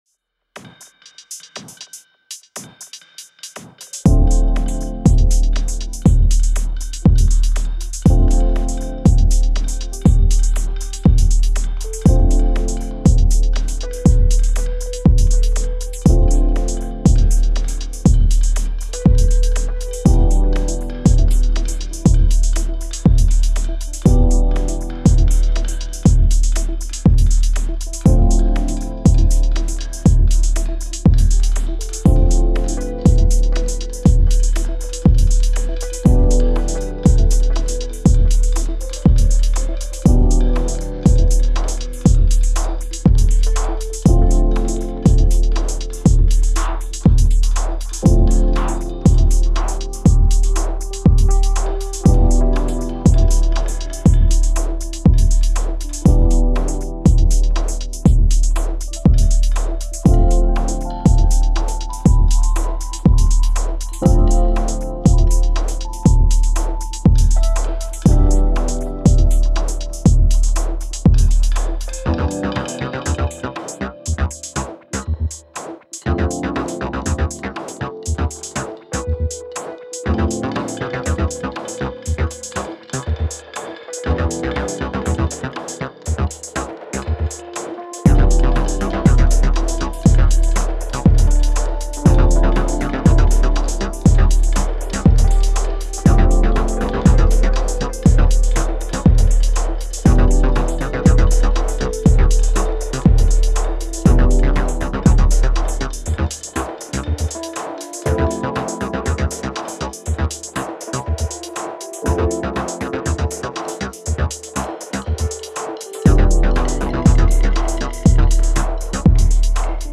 rhythmic psychedelic techno tracks
Electronix Techno Minimal